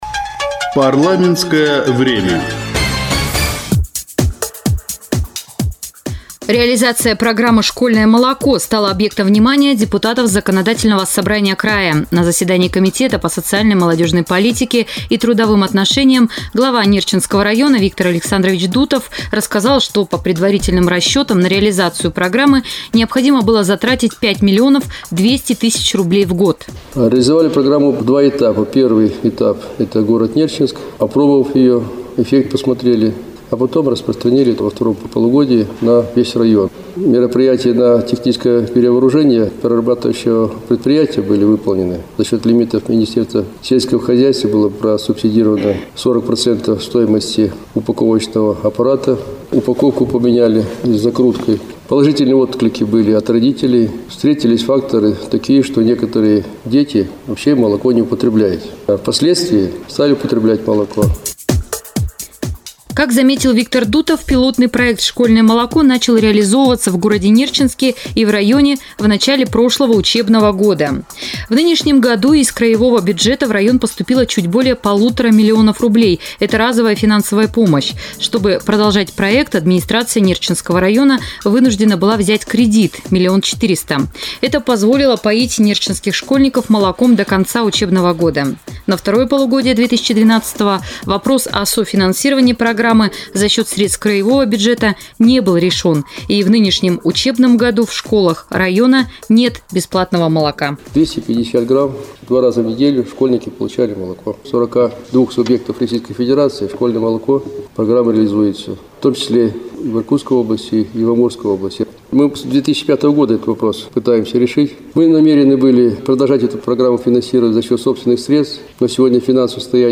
Информационый сюжет: "Школьное молоко"